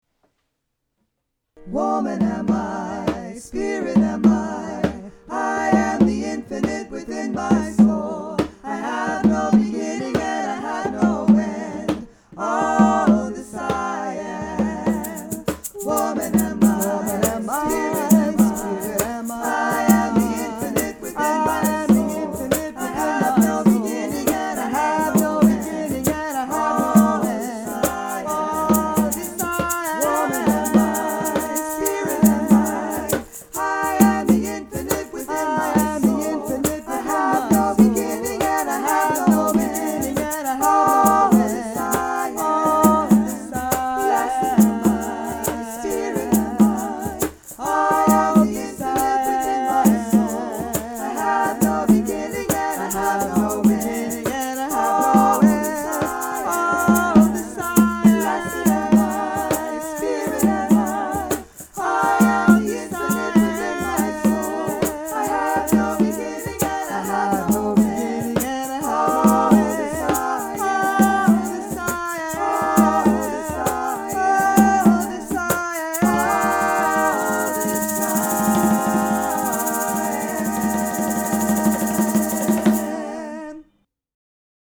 SSAA a cappella (opt. hand perc.)